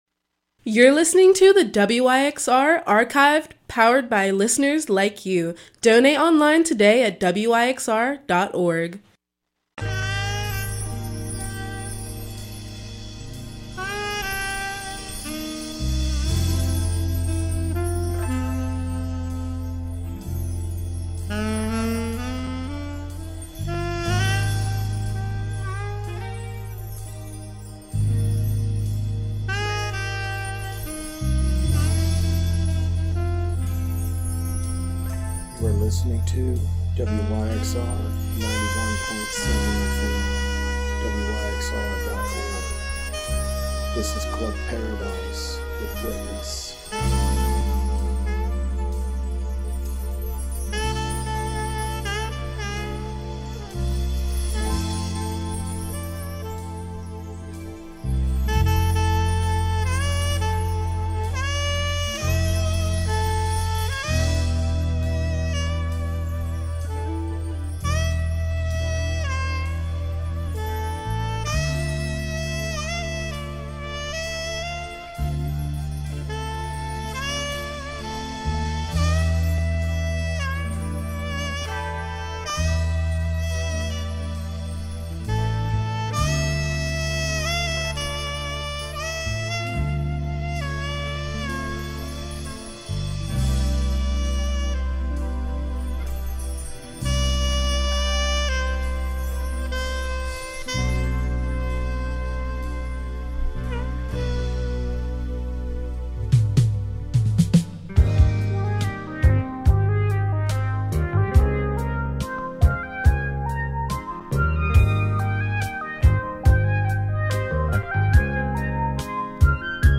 Funk Soul